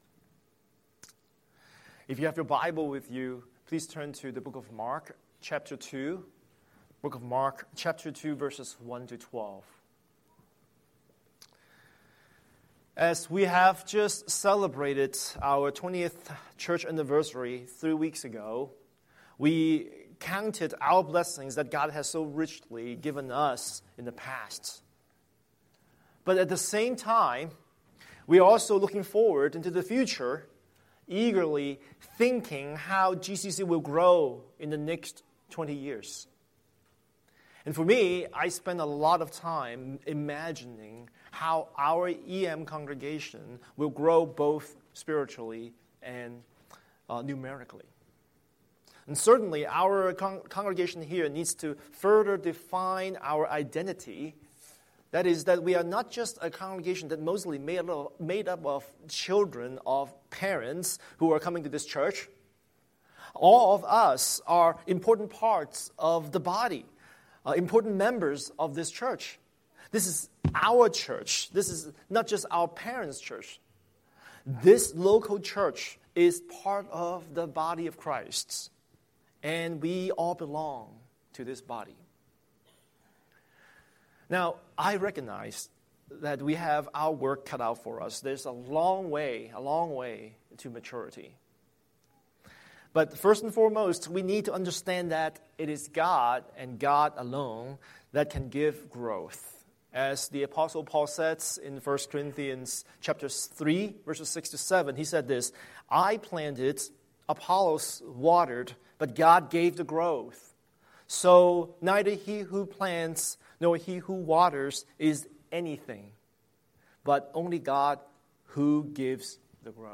Scripture: Marks 2:1-12 Series: Sunday Sermon